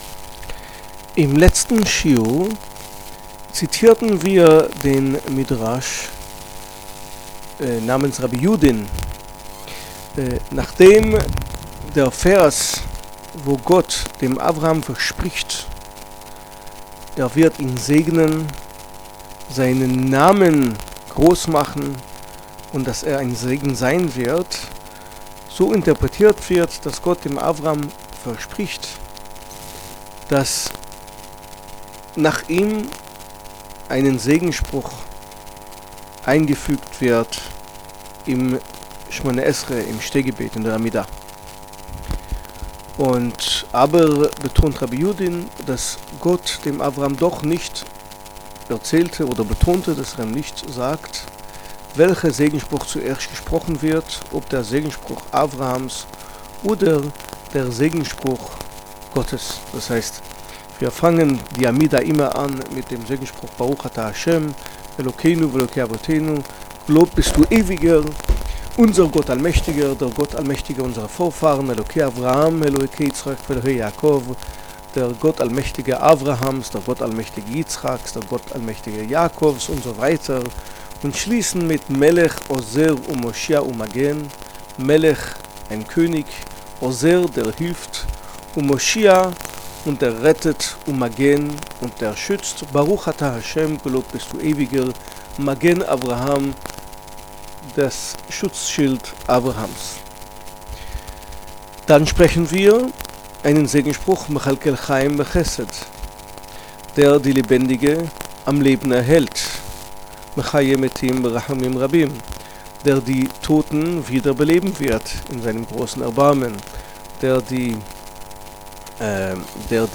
G”tt erklärte dem Stammvater Abraham, er werde ein Segen, und alle Geschlechter auf Erde werden durch Abraham und seinen Nachkommen gesegnet werden. Diese zwei Stellen müssen nicht nur interpretiert werden, sondern werfen auch theologische Fragen auf, deren Antworte seither das jüdische Leben prägen. Zur Vortrags-Serie: Obwohl er eine der bedeutendsten biblischen Persönlichkeiten ist, erzählt die Torá immerhin nur einige ausgewählte Kapitel des Lebens Abrahams.